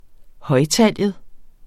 Udtale [ ˈhʌjˌtalˀjəð ]